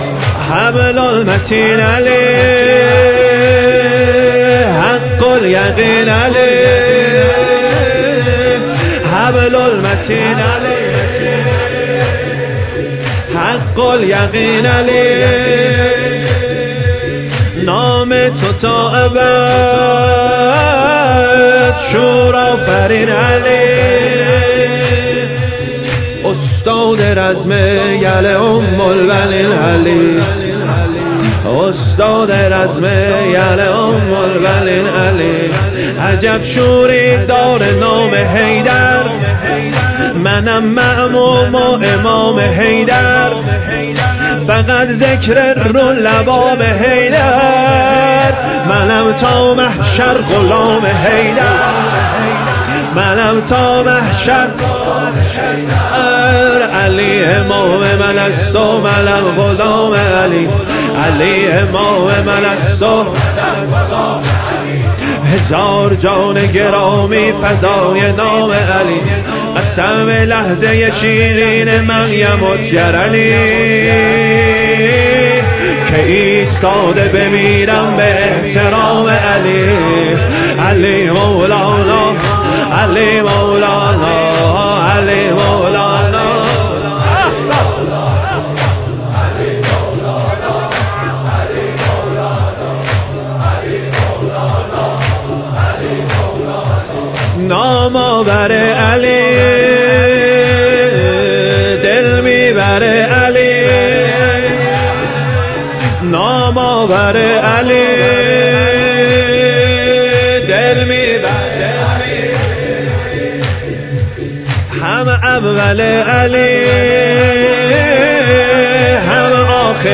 زمینه | حبل المتین علی
سینه زنی زمینه
ایام فاطمیه دوم - شب دوم